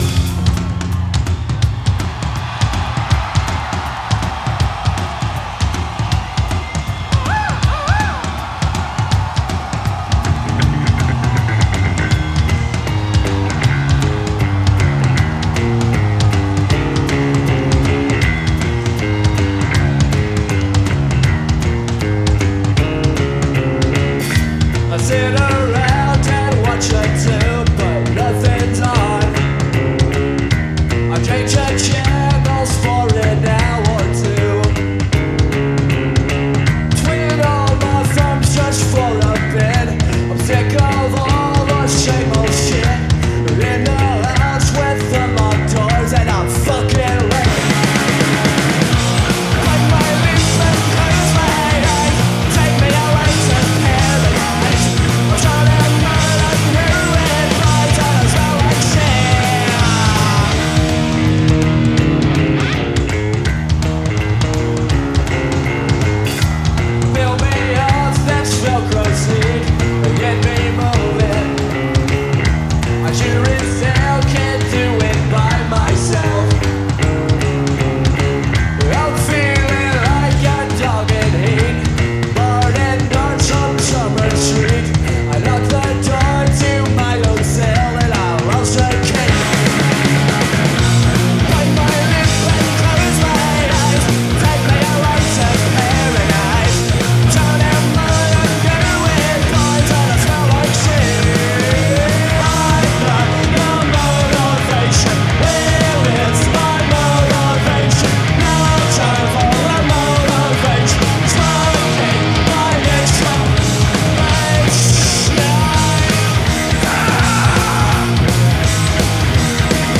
Live at Woodstock 1994